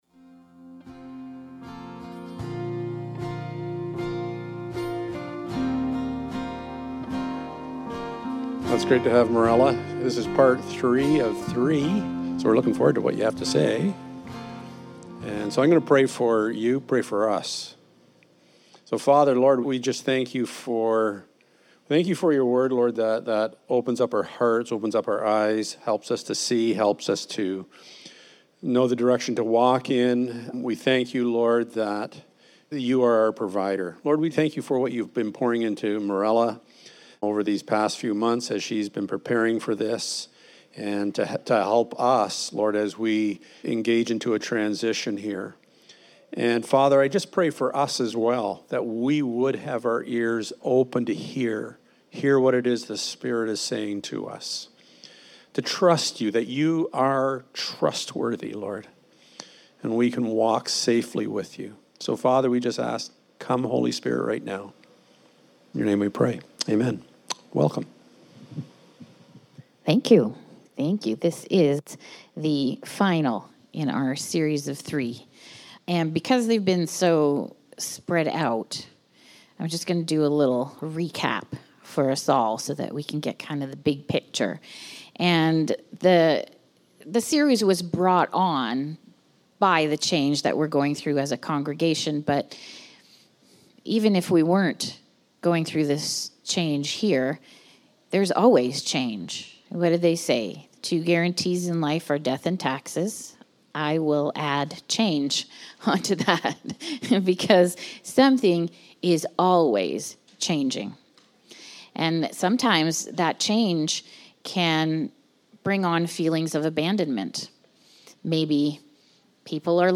Guest Speaker Service Type: Sunday Morning It’s been said that the only guarantees in life are death and taxes.